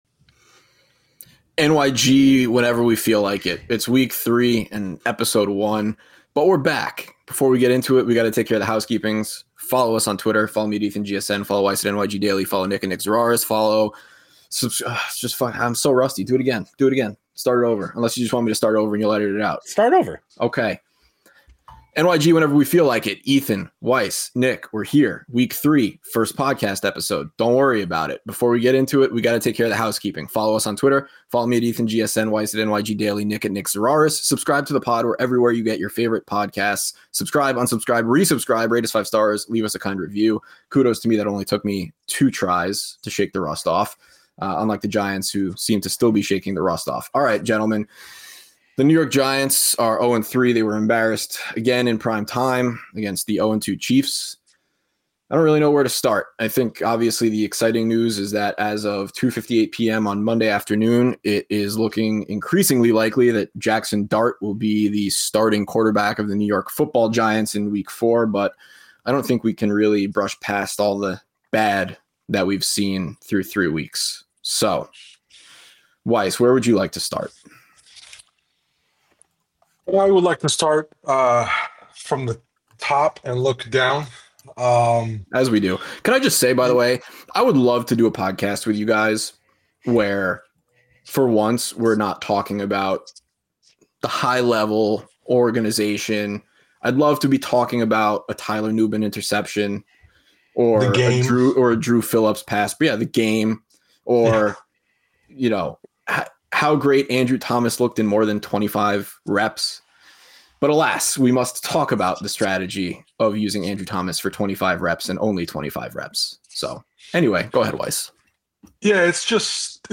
1 POWER COUPLE: Tobin Heath & Christen Press Join Us LIVE + What’s Next for Trinity Rodman? 1:05:24 Play Pause 13d ago 1:05:24 Play Pause Play later Play later Lists Like Liked 1:05:24 This week on A Touch More, we’re live from San Jose, talking to NWSL legends Tobin Heath and Christen Press about the league’s growth, why Trinity Rodman shouldn’t go abroad and what they really think about soccer playoffs deciding the league champion.